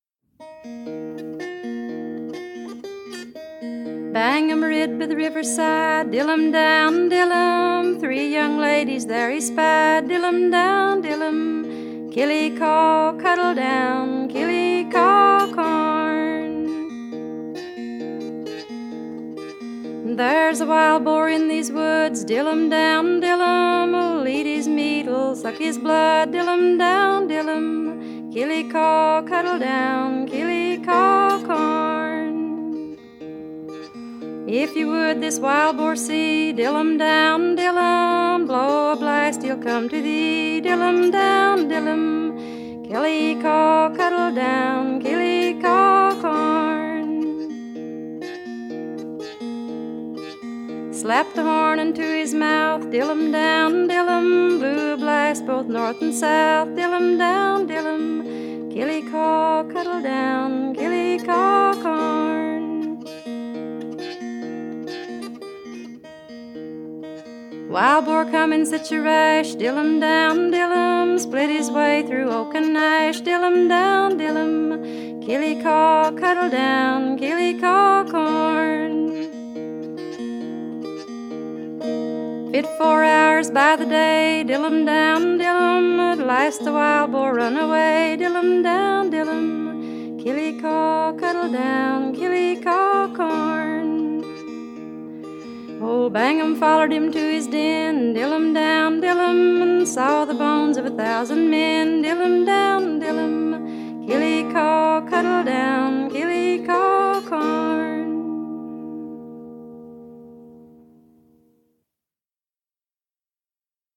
無伴奏によるアカペラと、自身が奏でるマウンテン・ダルシマーによる引き語りというとてもシンプルな形で録音された作品です
バラッドによくある死や裏切りなどのディープなテーマを、朴訥とした雰囲気で物悲しく歌っています！